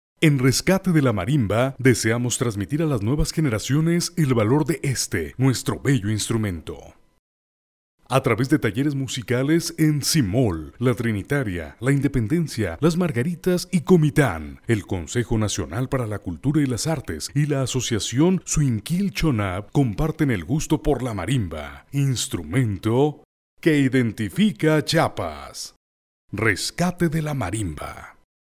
locutor versatil tanto para voz comercial, institucional, y doblaje
locutor profesional perfecto español
kastilisch
Sprechprobe: Werbung (Muttersprache):